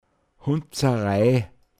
Pinzgauer Mundart Lexikon
Details zum Wort: Hundsarai. Mundart Begriff für Ärgernis